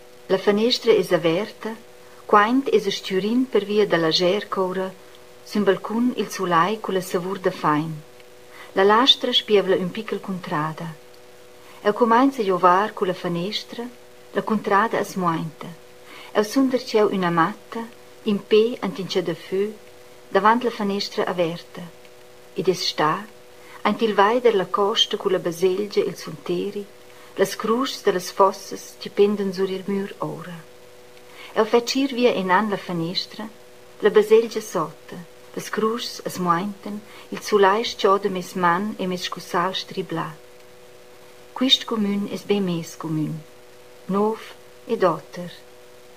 Here’s a recording of a poem in a mystery language.